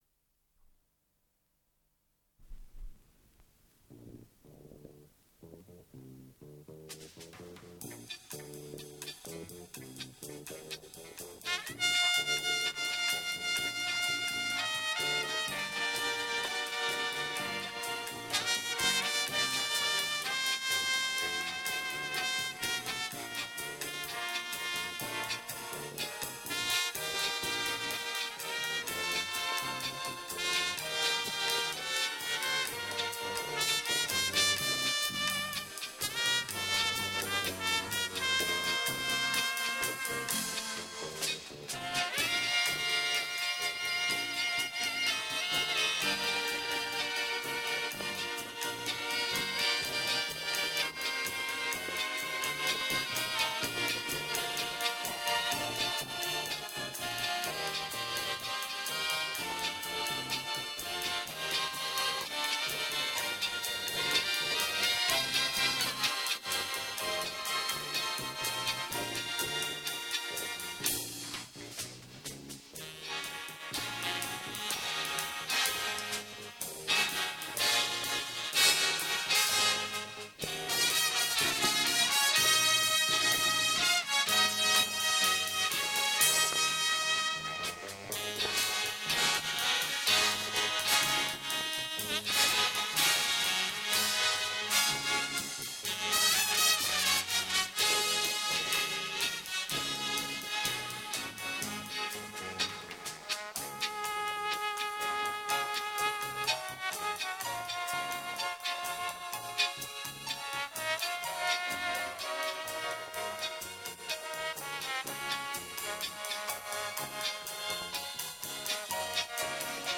Дубль моно.